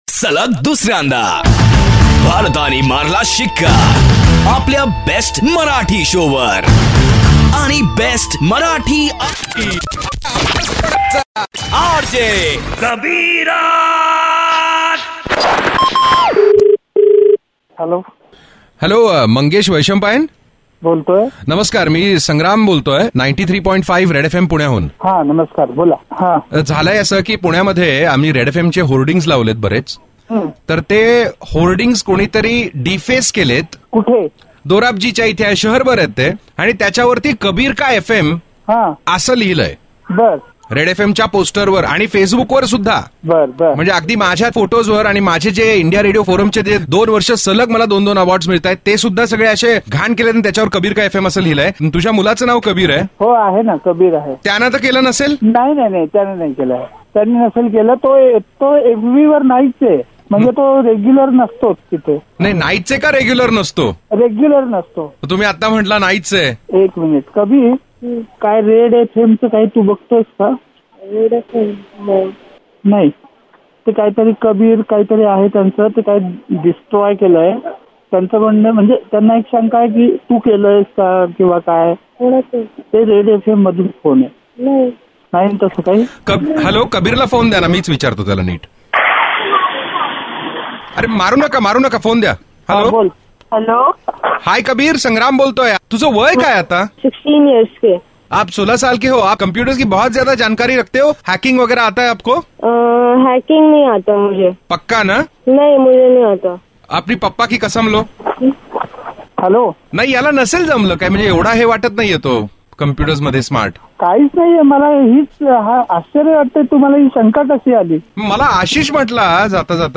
India Ka no. 1 Marathi Radio Show.